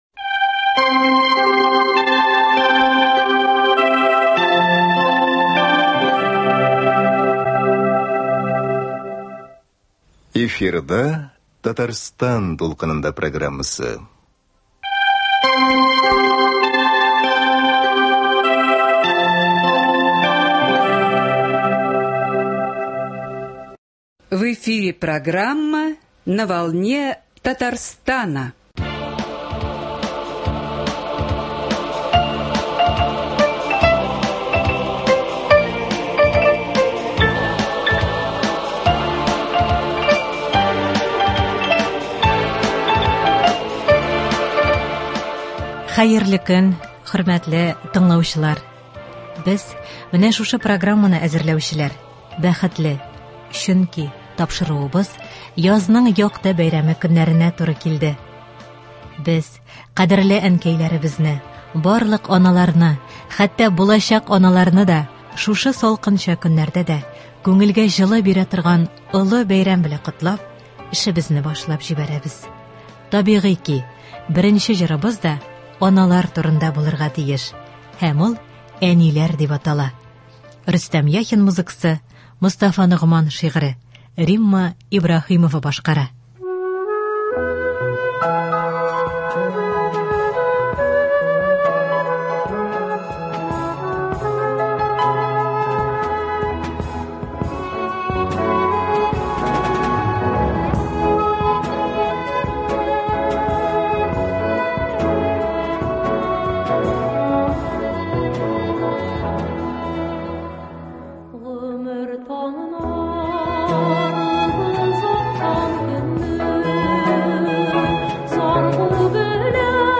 Тапшыруның икенче өлешендә игътибарыгызга “Гел Кояшка таба” дип исемләнгән әдәби-музыкаль композиция тәкъдим ителә.